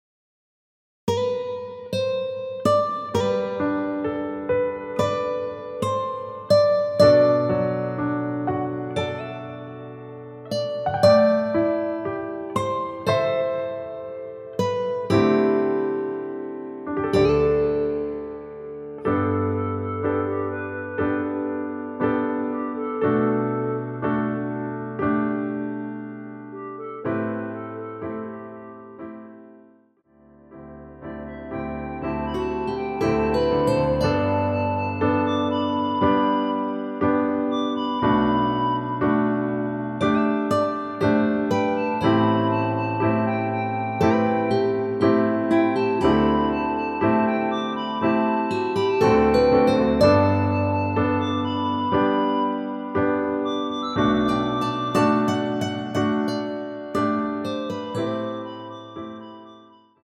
원키에서(+4)올린 멜로디 포함된 MR입니다.(미리듣기 확인)
앞부분30초, 뒷부분30초씩 편집해서 올려 드리고 있습니다.